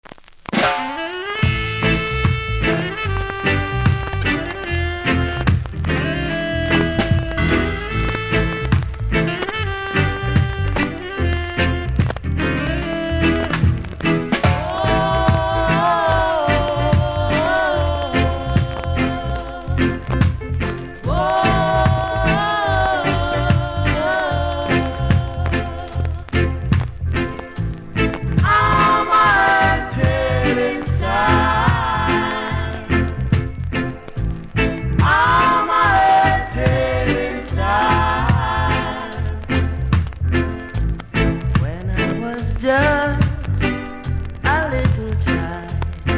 CONDITION：G- ( Crack )
残念ながら割れもありキズ多めで両面とも状態悪いです。